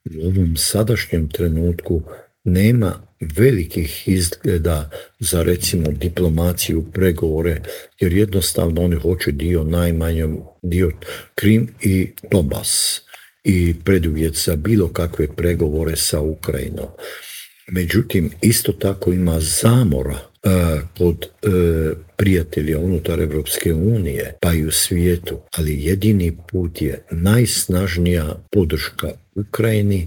Cilj je bio eliminirati predsjednika Franju Tuđmana, a tog se dana u Intervjuu tjedna Media servisa prisjetio bivši ministar vanjskih poslova Mate Granić.